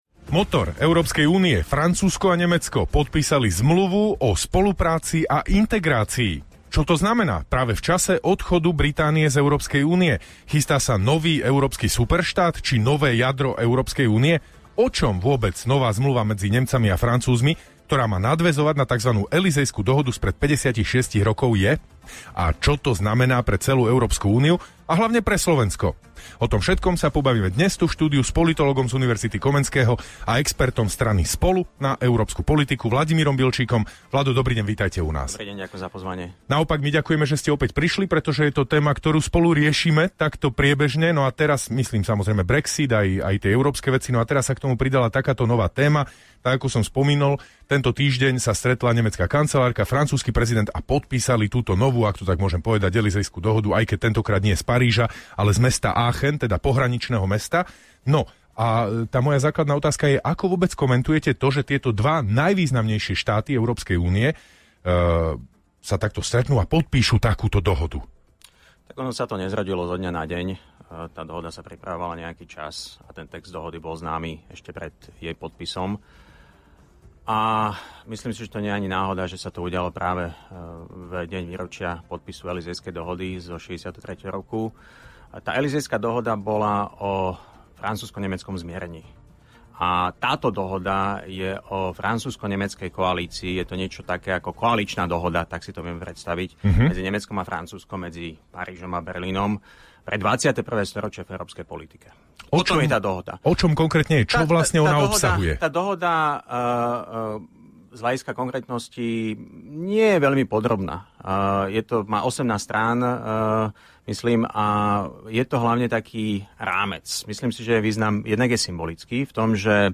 Čo dohoda znamená pre Európsku úniu a čo pre Slovensko? Braňo Závodský sa rozprával s politológom z Univerzity Komenského a expertom strany Spolu na zahraničnú politiku Vladimírom Bilčíkom.